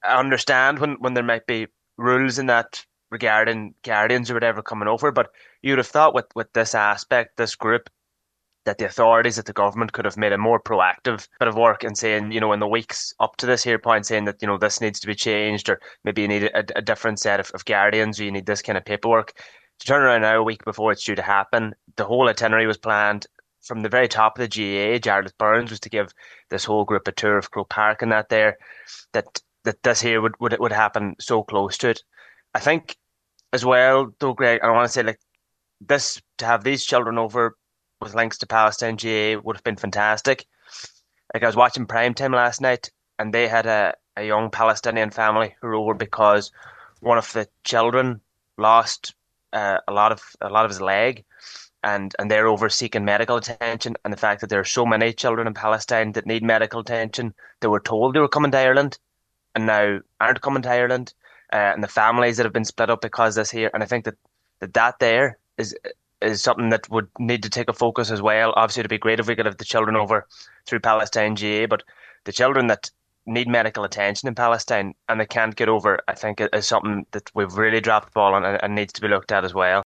Speaking on today’s Nine til Noon Show